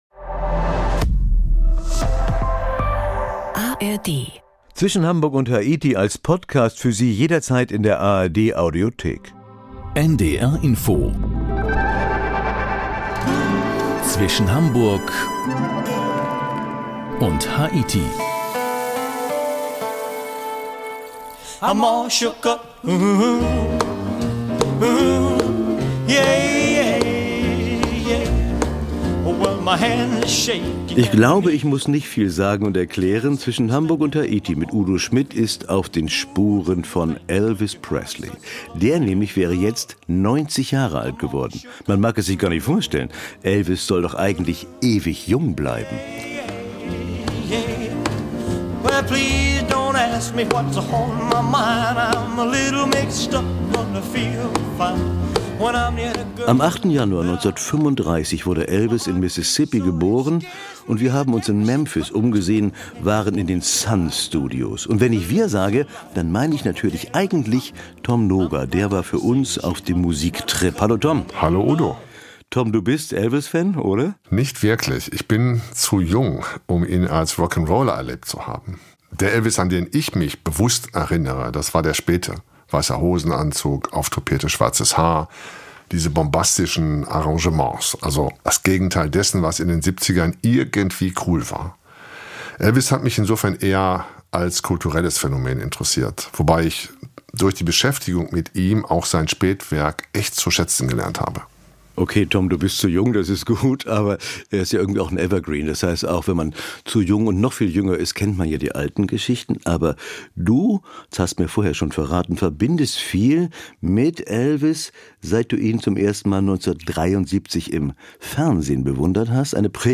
Mit dem Mikrofon rund um die Welt.
Wir fragen unsere Reporter*innen aber auch, wie sie selber sich gefühlt haben beim Reisen, wie es ist, fremd in fernen Welten zu sein.